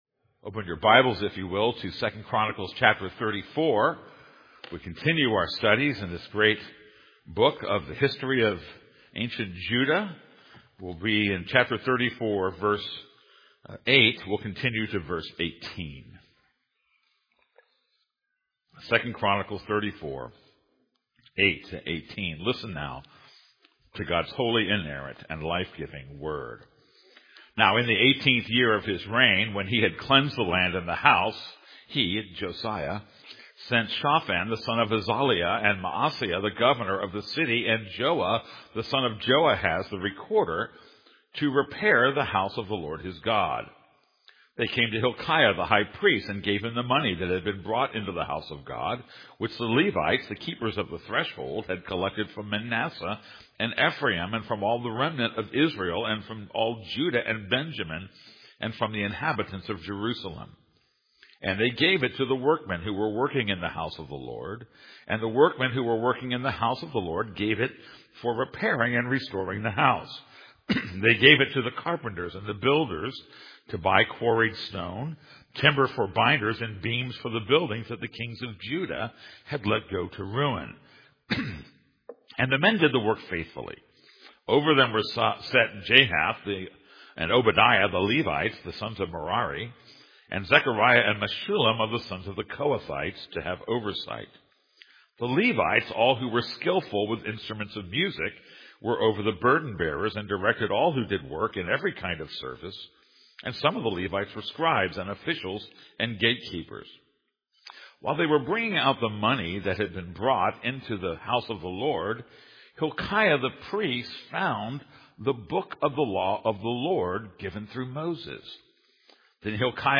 This is a sermon on 2 Chronicles 34:8-18.